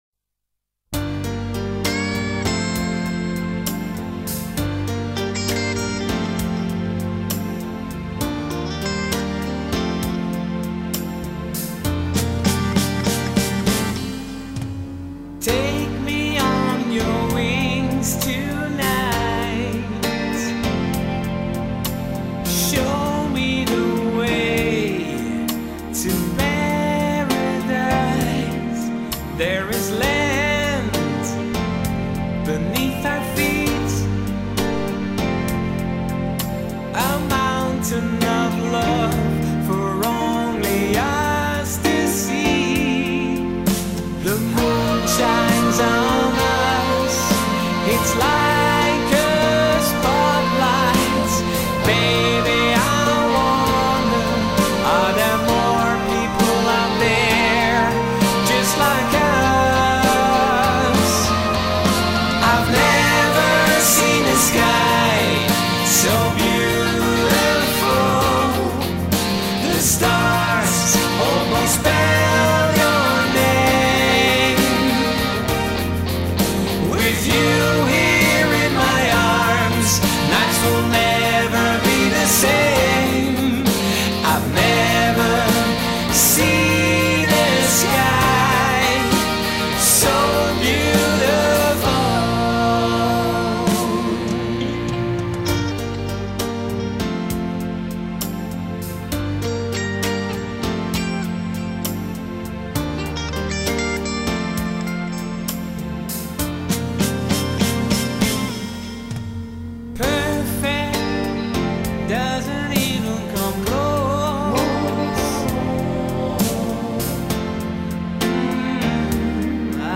lead vocals
guitar solo
rhythm guitars & clean solos
keyboards
bass guitar
drums
Background vocals